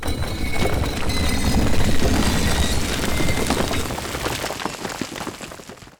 unblock.wav